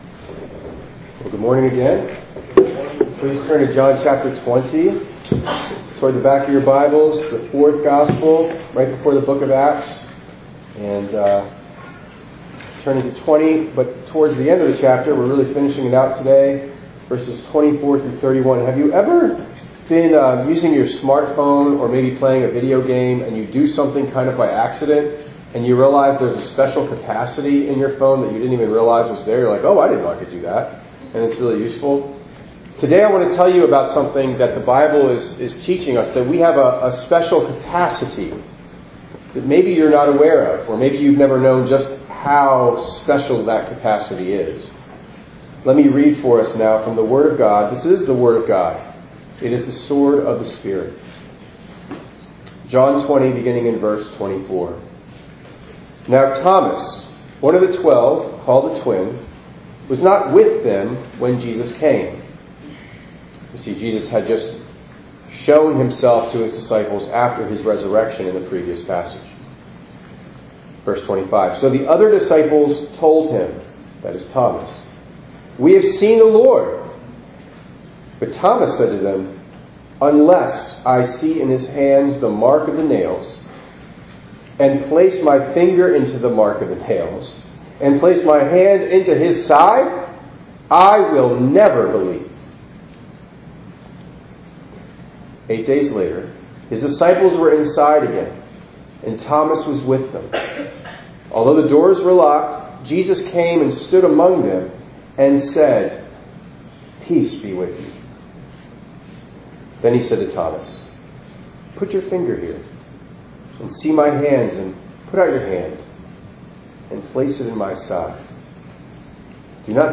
4_28_24_ENG_Sermon.mp3